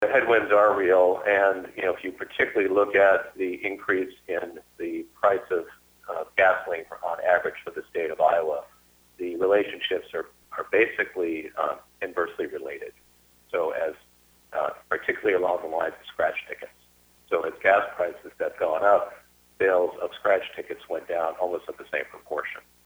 The two made their comments at the Iowa Lottery Board meeting held on Tuesday, September 27th.